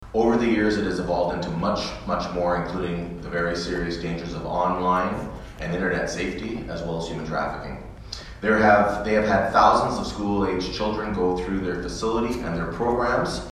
Speaking at the signing event, Panciuk said the move represents the correction of a mistake previous councils had made and that was not thinking about a location for the safety village when a new location was being considered for the Belleville Police Service.